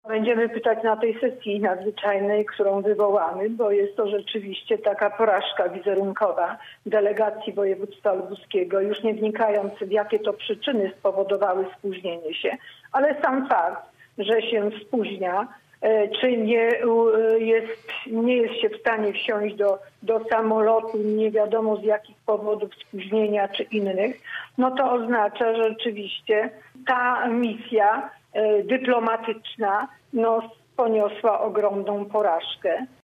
– Będziemy o to pytać podczas najbliższej sesji – mówi lubuska radna Aleksandra Mrozek z klubu Samorządowe Lubuskie: